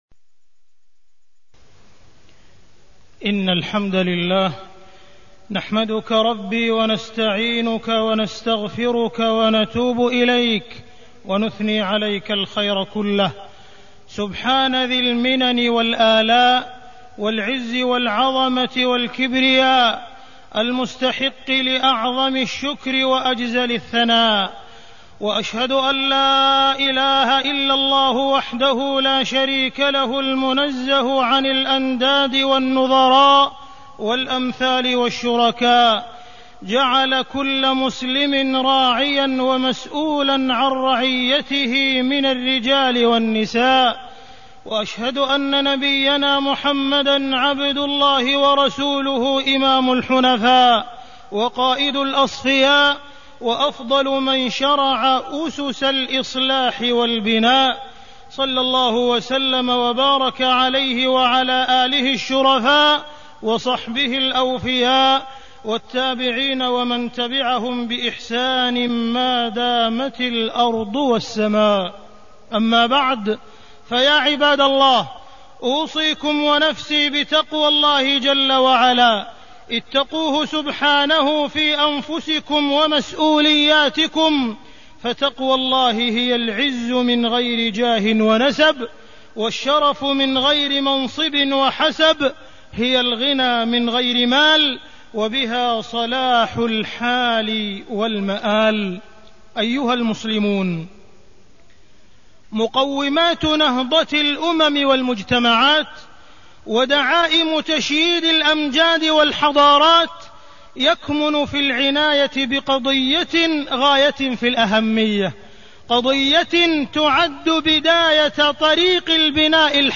تاريخ النشر ٣ ربيع الثاني ١٤٢٣ هـ المكان: المسجد الحرام الشيخ: معالي الشيخ أ.د. عبدالرحمن بن عبدالعزيز السديس معالي الشيخ أ.د. عبدالرحمن بن عبدالعزيز السديس المسؤولية في الإسلام